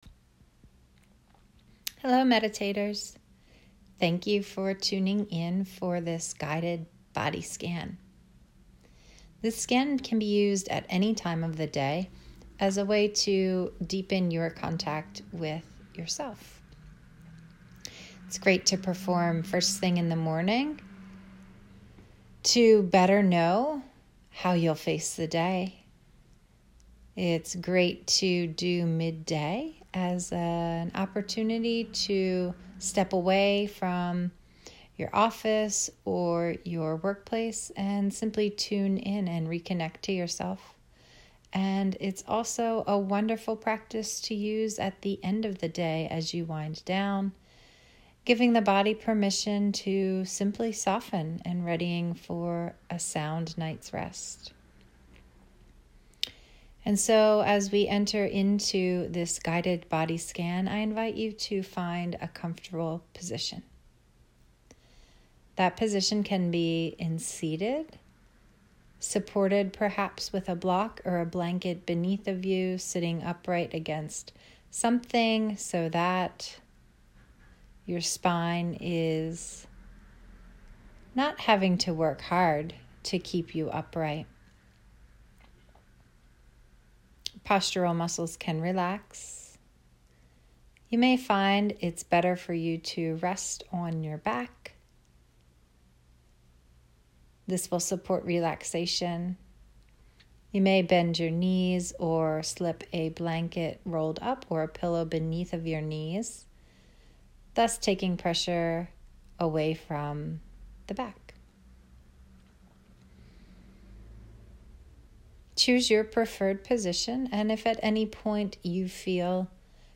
The following is a sample meditation for you to enjoy – A guided body scan! https
Guided-Body-Scan-1....mp3